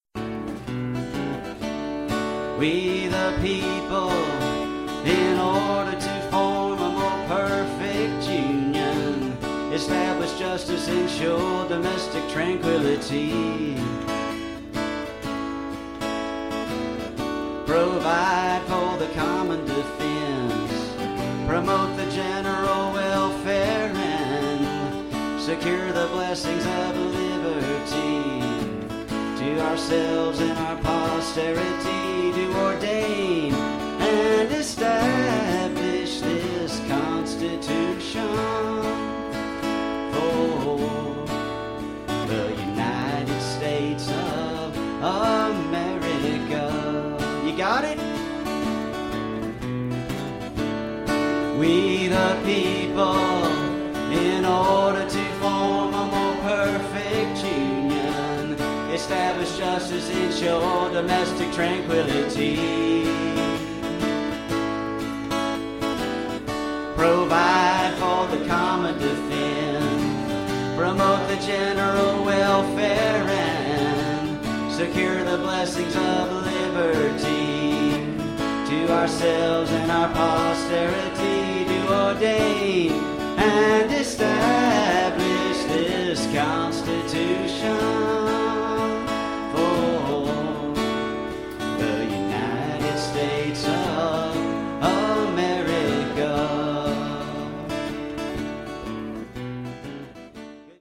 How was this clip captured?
Live on Liberty Stage (2012)